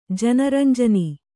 ♪ jana ranjani